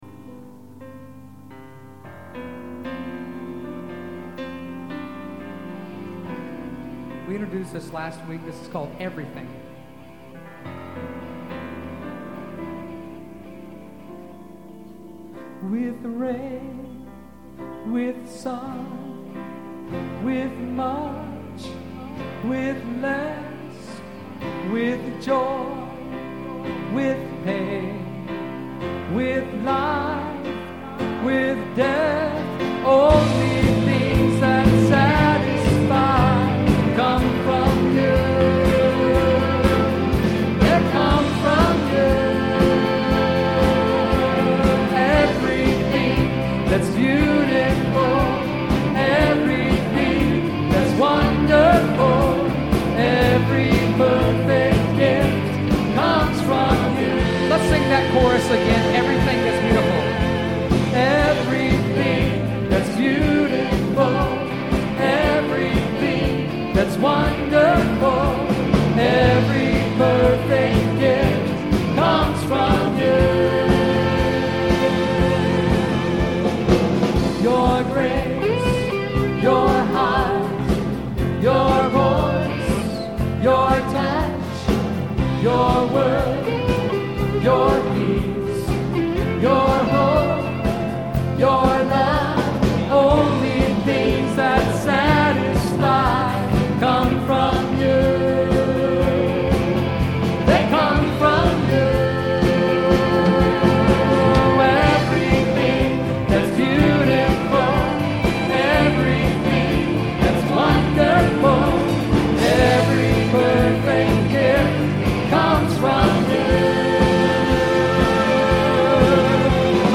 Praise Music
The five tracks below were recorded on my last Sunday with the band before being transferred to New Jersey.
Westminster Presbyterian Church Praise Band (May, 2003)